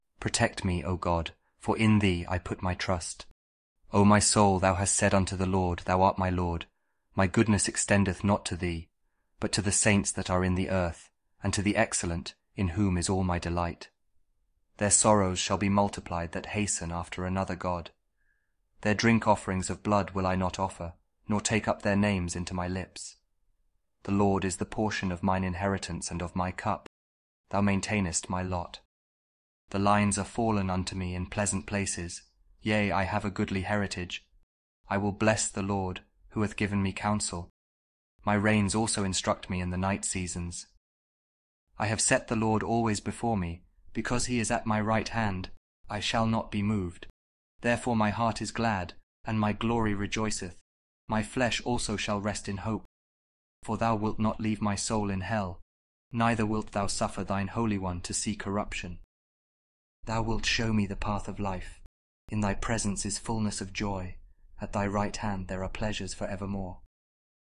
Psalm 16 | King James Audio Bible
016-psalm-king-james-audio-kjv.mp3